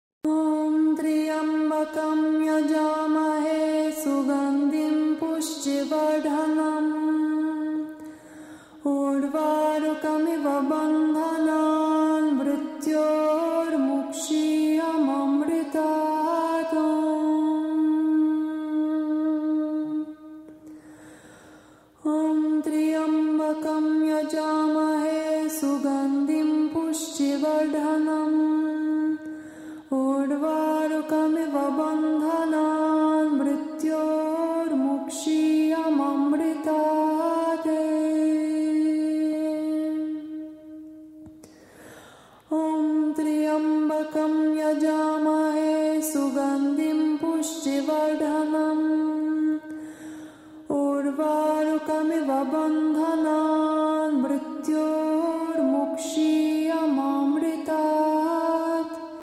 • Качество: 128, Stereo
женский вокал
восточные мотивы
спокойные